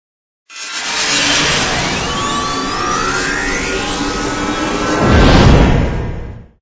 engine_no_cruise_start.wav